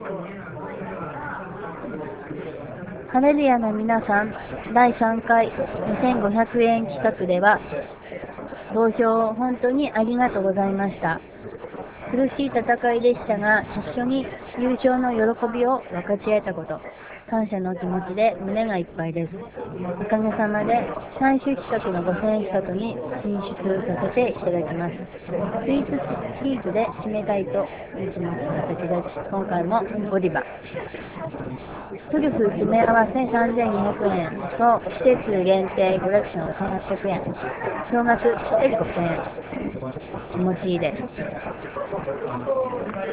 リポート等の音声はスタジオ録音ではなく現地録りになりますので他の人の声などの雑音が入っていたりしますがご了承願います。